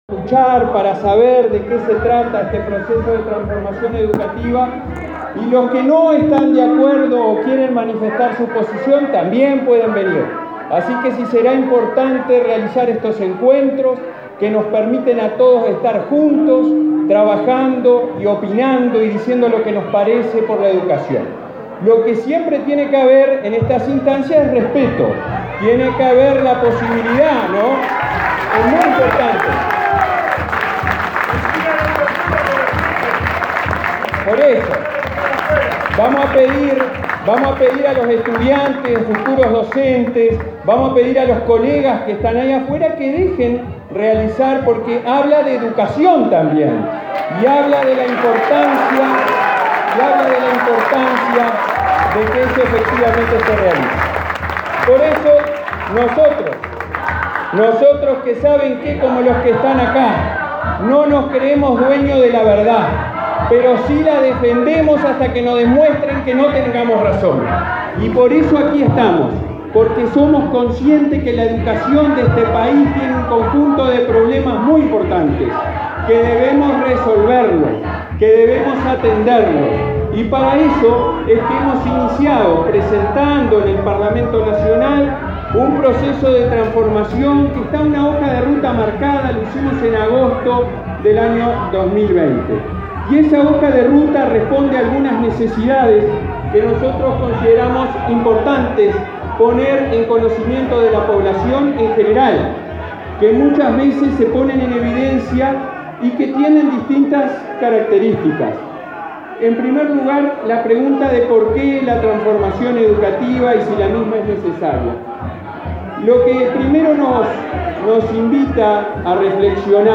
Palabras del presidente del Codicen de la ANEP, Robert Silva
Palabras del presidente del Codicen de la ANEP, Robert Silva 02/09/2022 Compartir Facebook X Copiar enlace WhatsApp LinkedIn El presidente del Consejo Directivo Central (Codicen) de la Administración Nacional de Educación Pública (ANEP), Robert Silva, participó, este 1.° de setiembre, en un Encuentro Cara a Cara con la Comunidad en el barrio Cerro, en Montevideo.